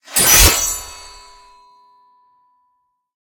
bsword3.ogg